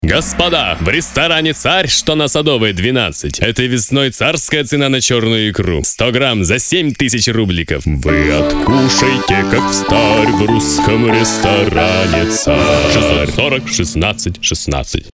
Музыка и монтаж для рекламных аудио роликов.